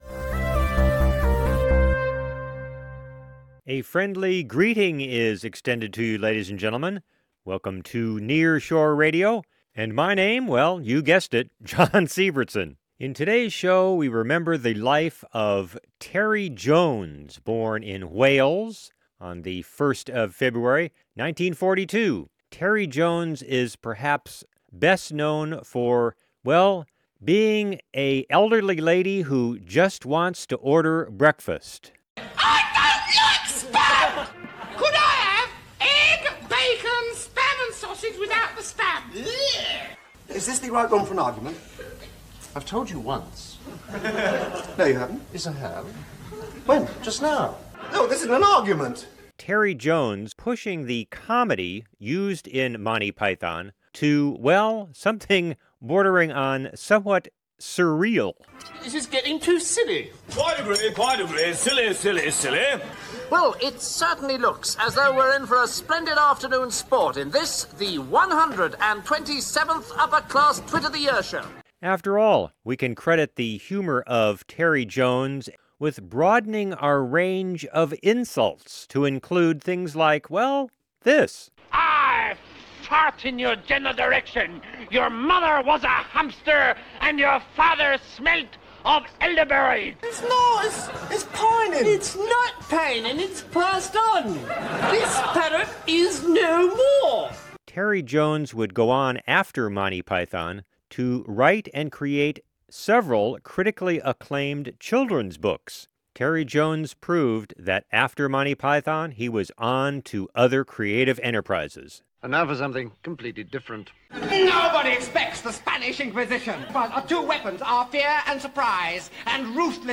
NEAR SHORE RADIO SHOW ON JONES: 3-min clip….